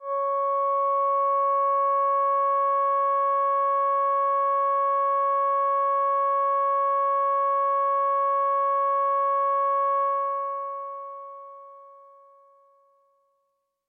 标签： MIDI-速度-32 CSharp6 MIDI音符-85 罗兰木星-4 合成器 单票据 多重采样
声道立体声